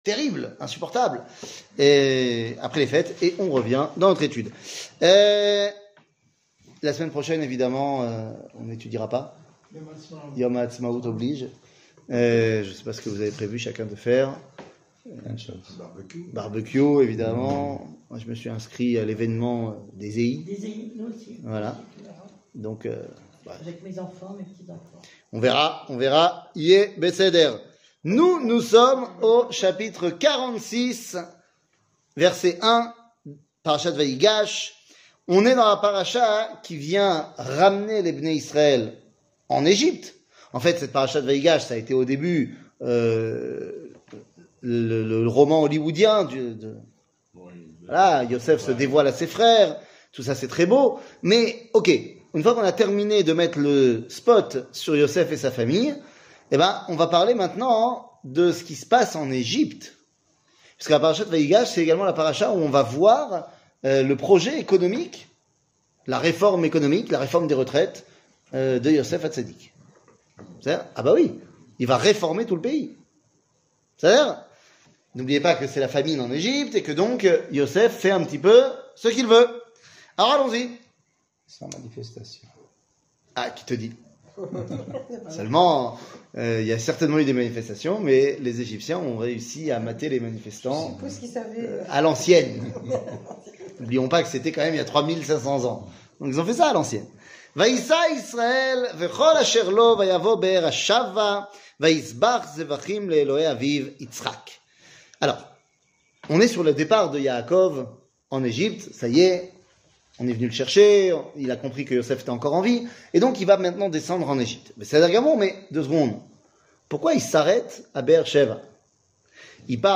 Livre de Berechit, chapitre 46, verset 1 00:44:11 Livre de Berechit, chapitre 46, verset 1 שיעור מ 20 אפריל 2023 44MIN הורדה בקובץ אודיו MP3 (40.44 Mo) הורדה בקובץ וידאו MP4 (76.19 Mo) TAGS : שיעורים קצרים